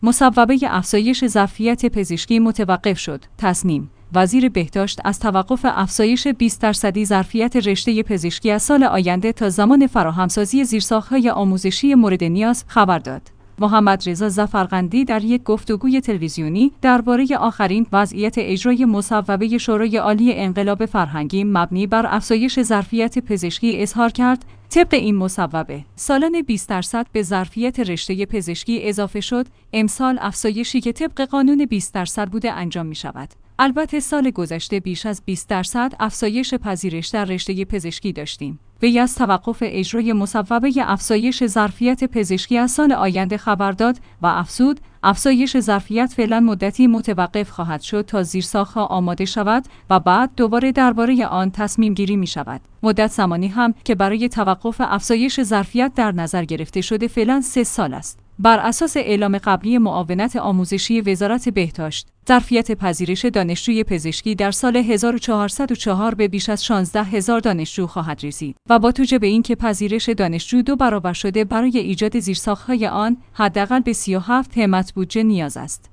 تسنیم/ وزیر بهداشت از توقف افزایش ۲۰ درصدی ظرفیت رشته پزشکی از سال آینده تا زمان فراهم‌سازی زیرساخت‌های آموزشی موردنیاز، خبر داد.